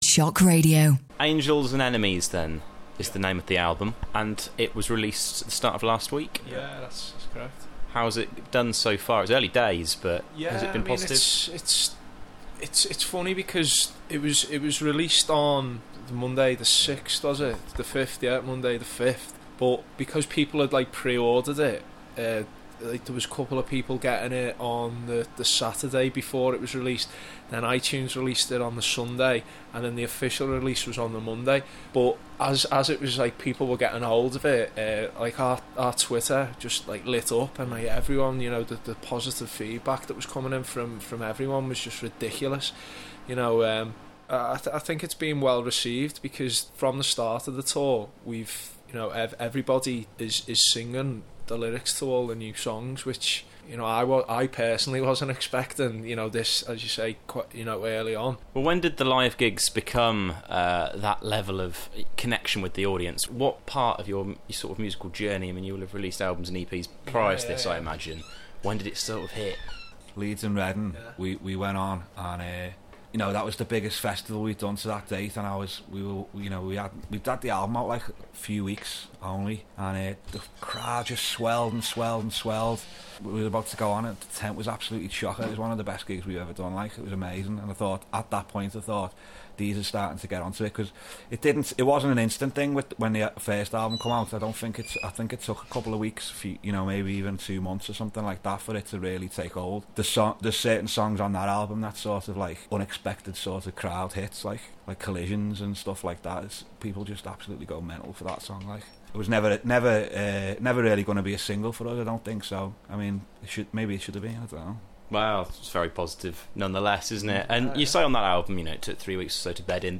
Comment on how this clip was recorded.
A chat with Sound of Guns at Manchester Roadhouse